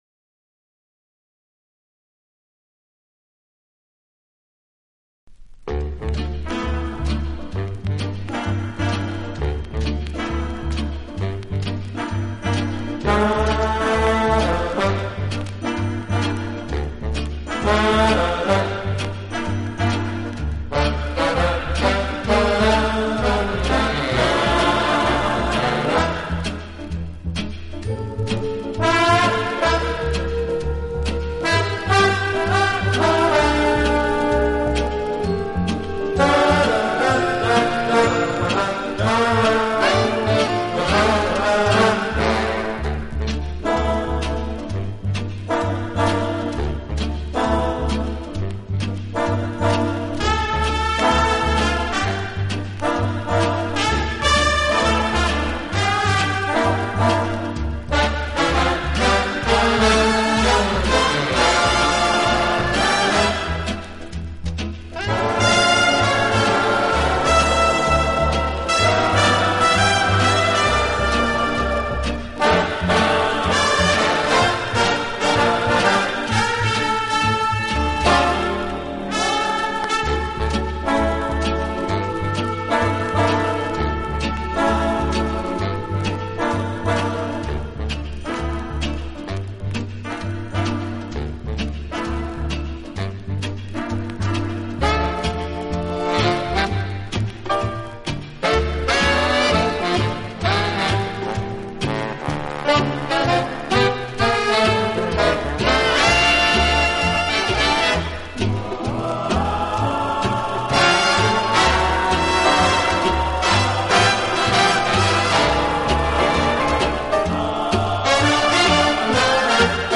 【轻音乐专辑】